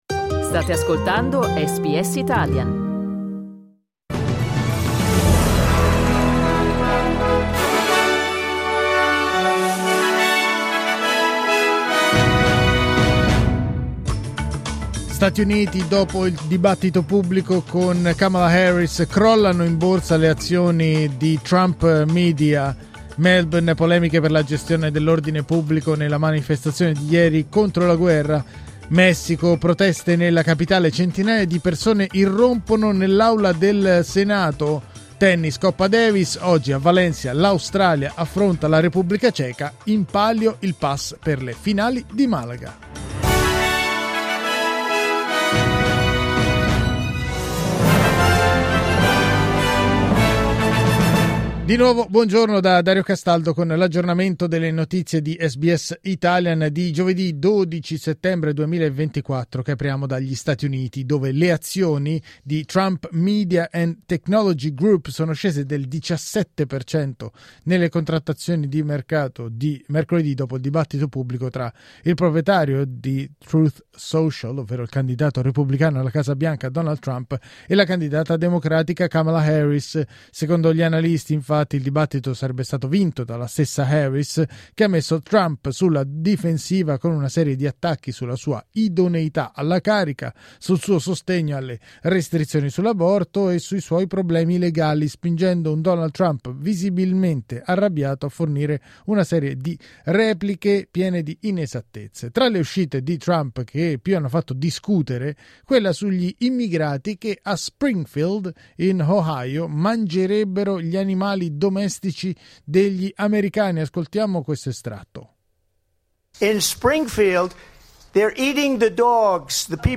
News flash giovedì 11 settembre 2024